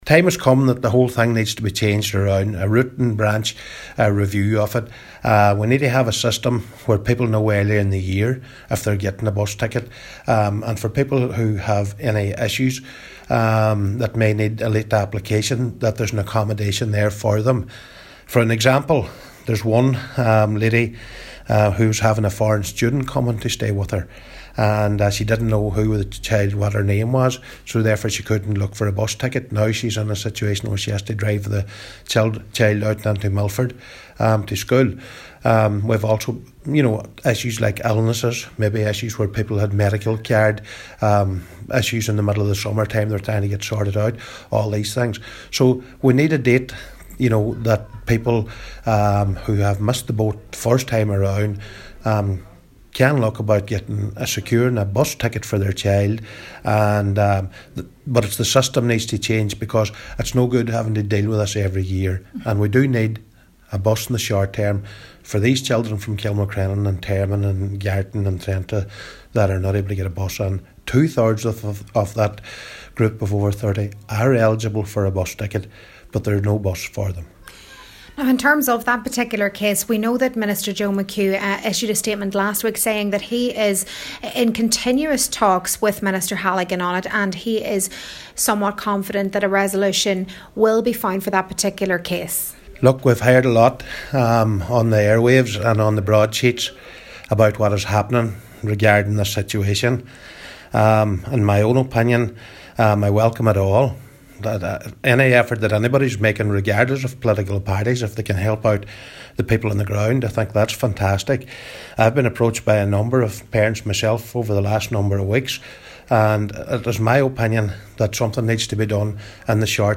Cllr. McBride says while local representatives can voice concerns, it’s down to the Government to address the issue: